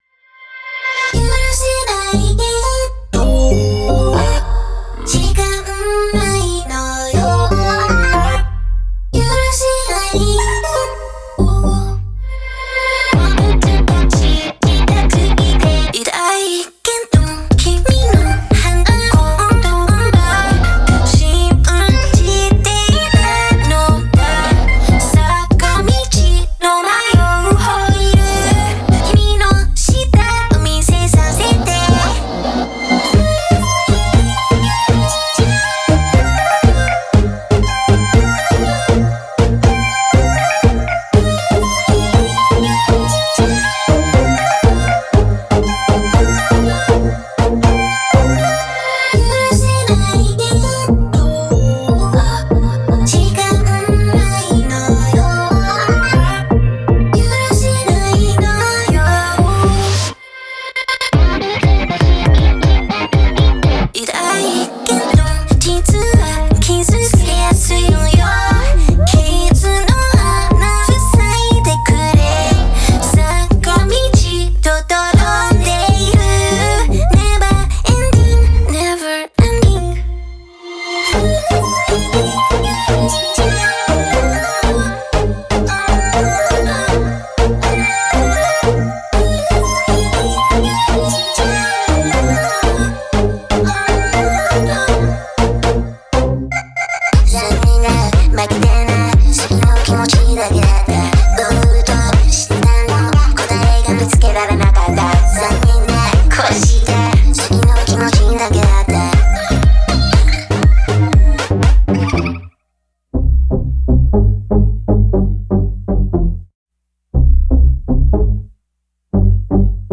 BPM120
MP3 QualityMusic Cut